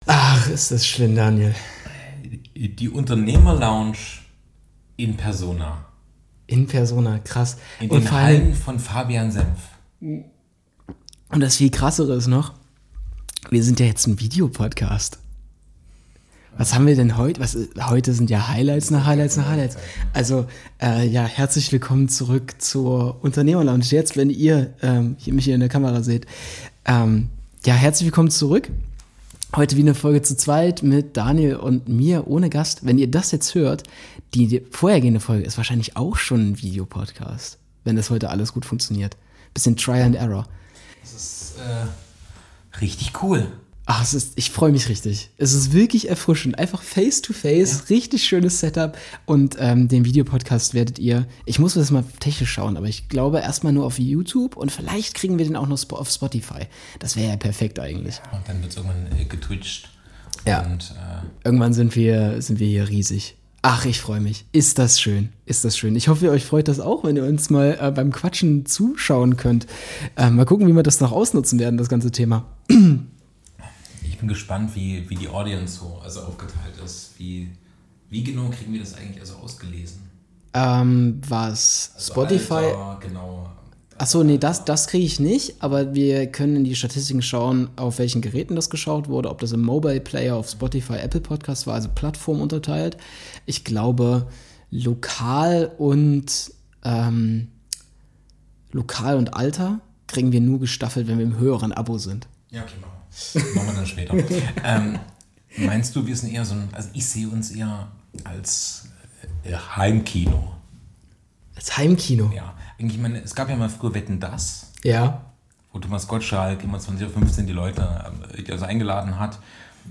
Ohne Gast, aber dennoch tiefgründig, thematisieren sie den beruflichen Alltag, Berufsethik und Zukunftsvisionen für ihren Podcast. Abschließend wird über das Potenzial von sportlichen Aktivitäten als Networking-Tools nachgedacht und die persönliche sowie berufliche Weiterentwicklung reflektiert.